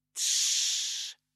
Звуки тс-с
Мужчина шипит прося говорить потише соблюдайте тишину